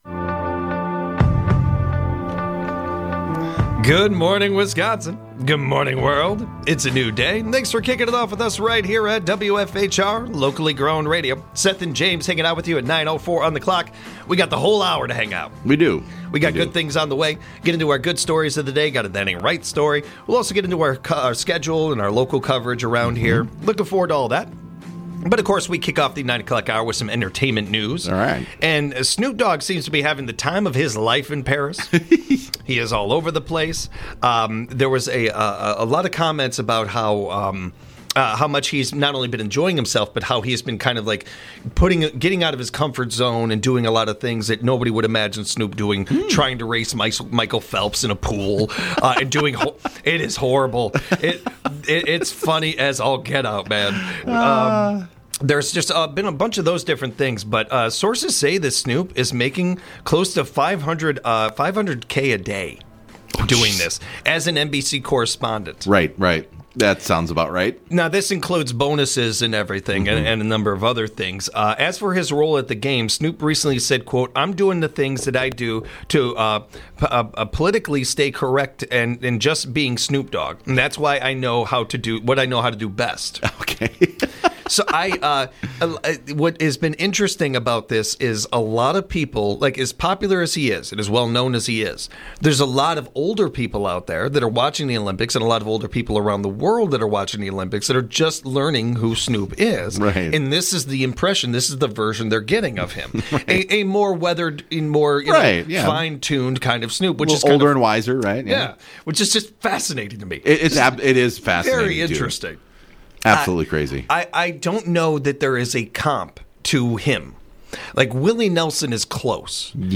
along with a rotation of entertaining co-hosts